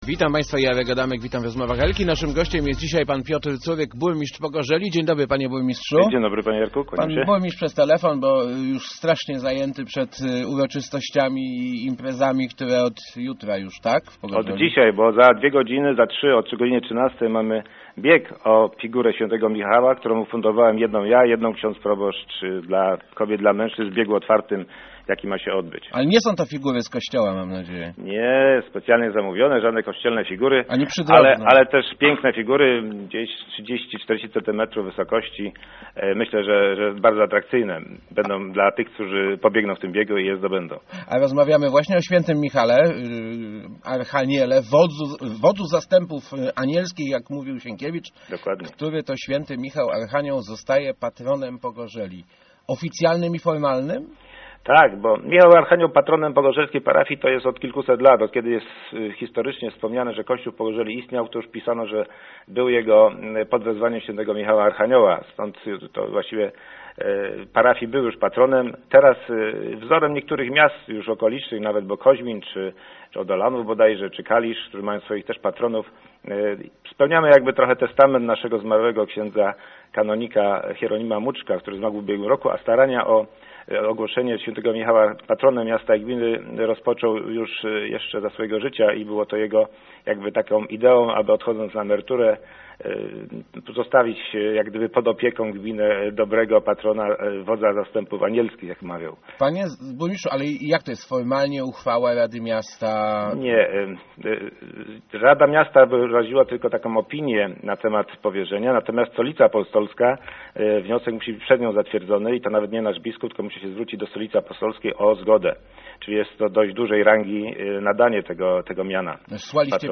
burmistrzpc.jpgOficjalne uznanie św. Michała Archanioła za patrona pozwoli nam lepiej walczyć ze złem - mówił w Rozmowach Elki Piotr Curyk, burmistrz Pogorzeli. Uroczystość przyjęcia patronatu odbędzie się w niedzielę 26 września. Gmina musiała zdobyć specjalną zgodę Stolicy Apostolskiej.